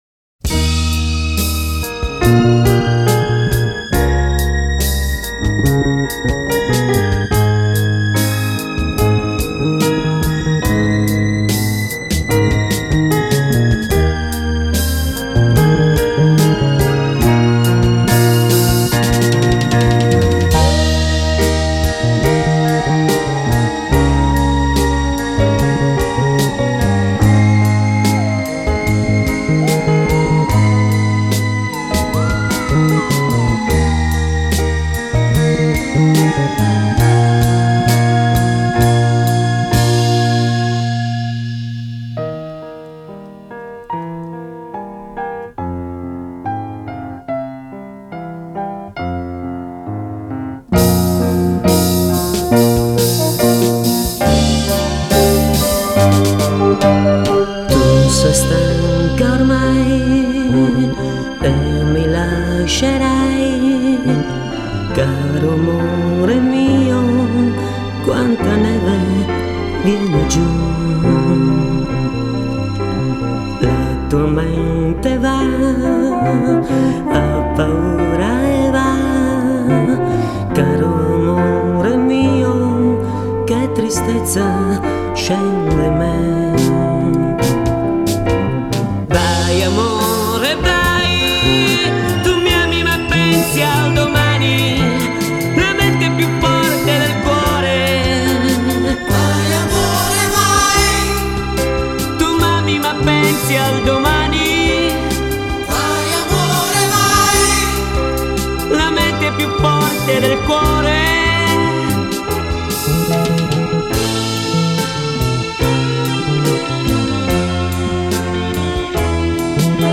Итальянская эстрада
vocals, keyboard
guitar
drums
bass